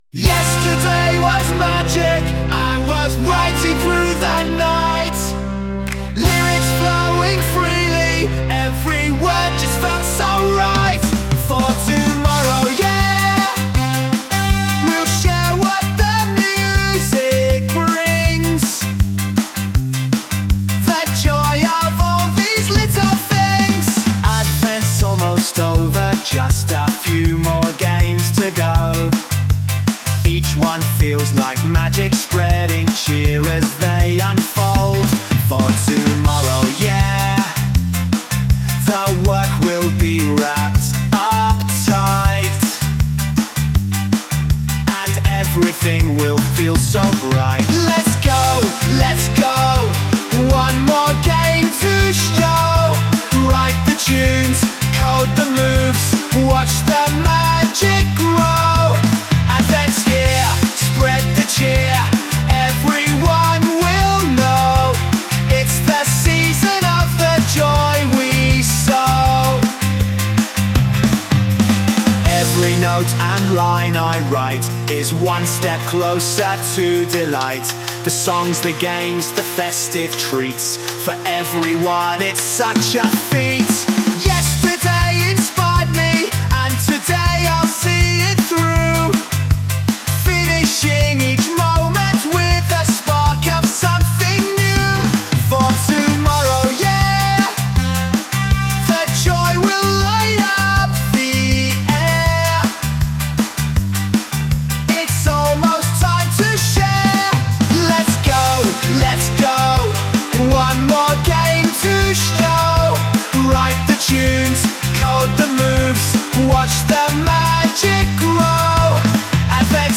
Reggae version
Sung by Suno